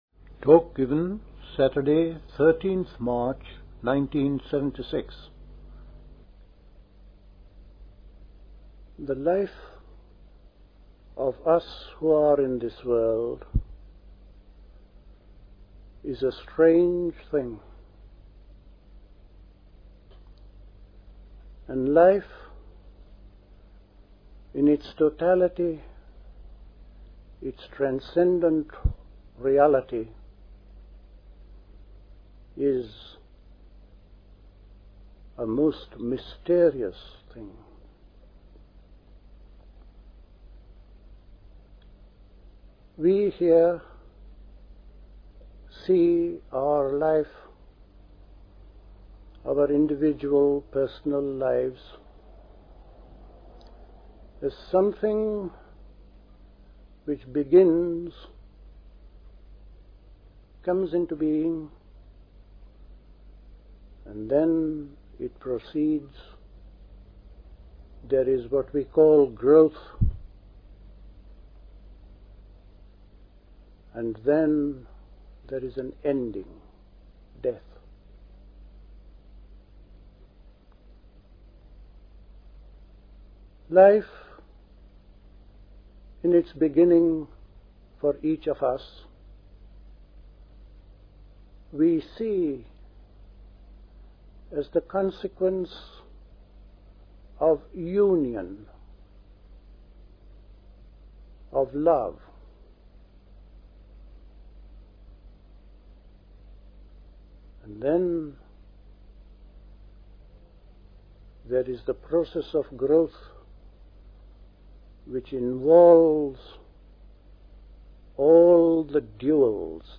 Talks